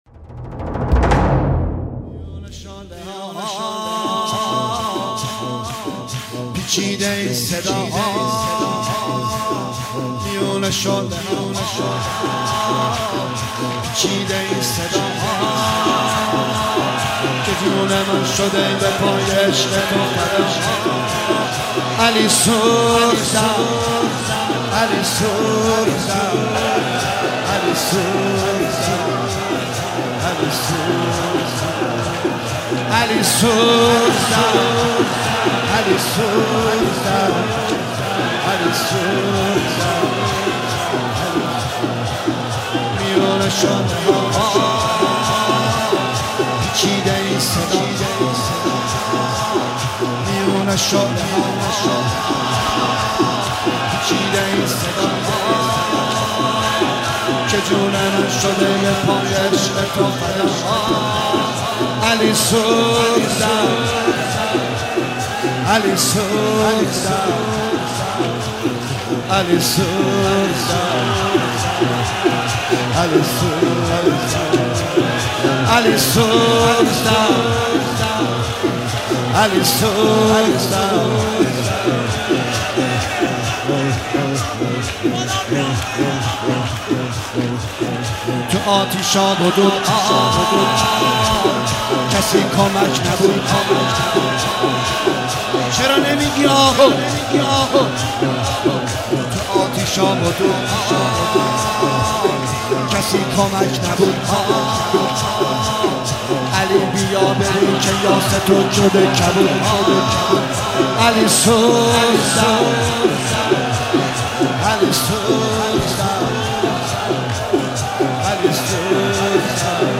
میون شعله ها افزدون صدای گریه و زجه